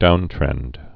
(dountrĕnd)